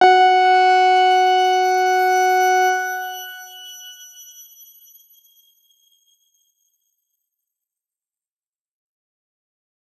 X_Grain-F#4-mf.wav